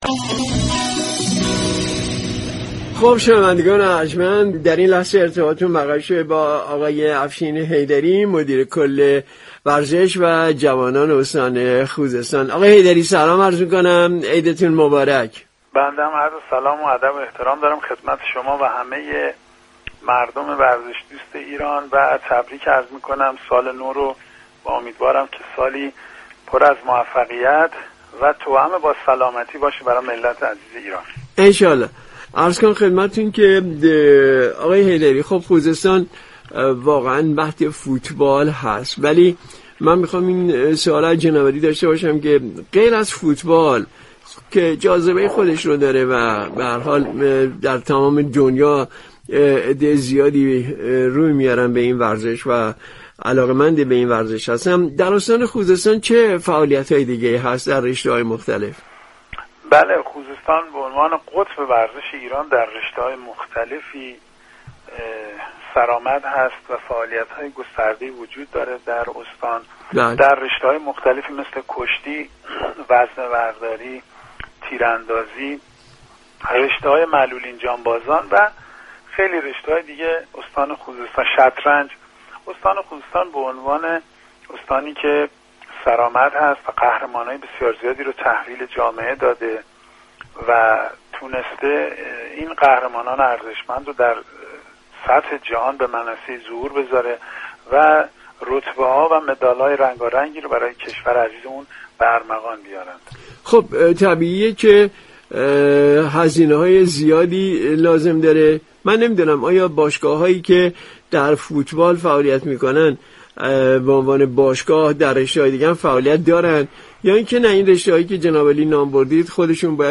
به گزارش رادیو ورزش؛ افشین حیدری، مدیركل ورزش و جوانان استان خوزستان در برنامه "ایران ما" درباره وضعیت رشته های مختلف ورزشی در این استان و ضعف هایی كه وجود دارد، توضیحاتی را ارائه كرد كه شما مخاطب محترم می توانید از طریق فایل صوتی پیوست شنونده این گفتگو باشید. مجله رادیویی «ایران ما» آداب و رسوم نوروزی در استان‌ ها و پیشكسوتان استان‌ ها را معرفی و همچنین چشم انداز فعالیت های ورزشی را بررسی می‌ كند.